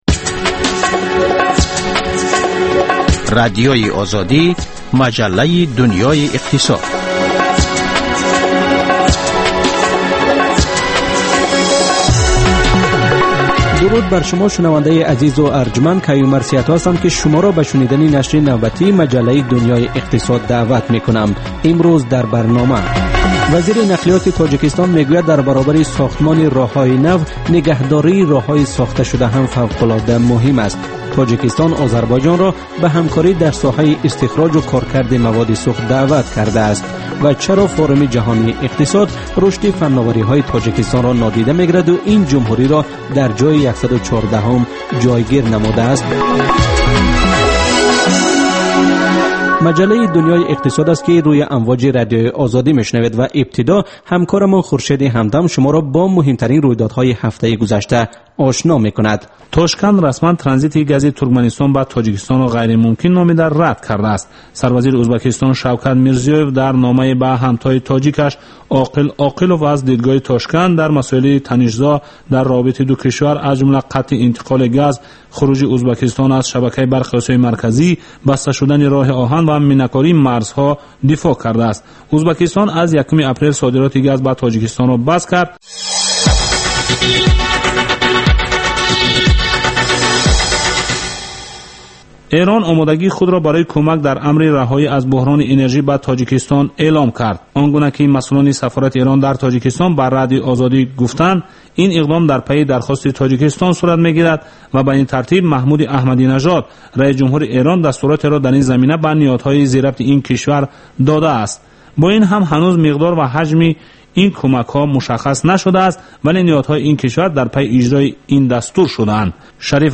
Хабарҳои иқтисодии Тоҷикистон, минтақа ва ҷаҳон. Баррасии фарояндаҳо ва падидаҳои муҳим дар гуфтугӯ бо коршиносон.